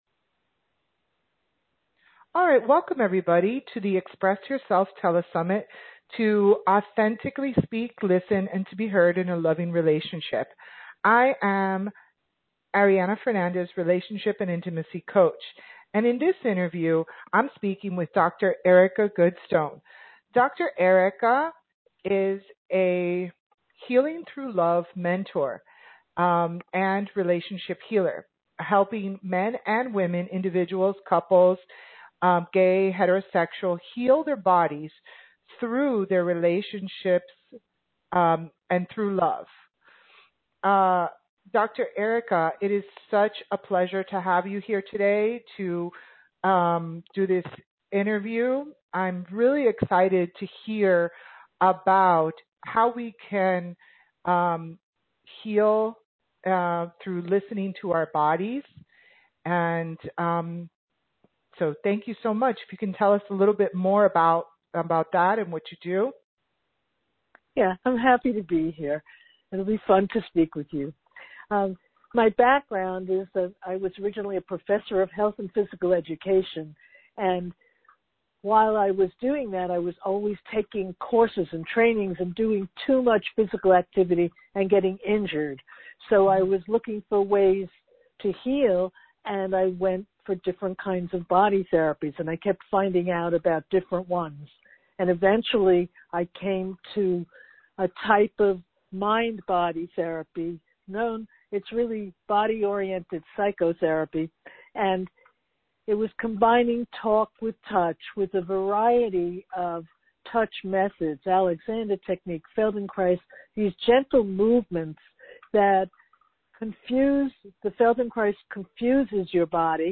EXPRESS YOURSELF AUDIO INTERVIEW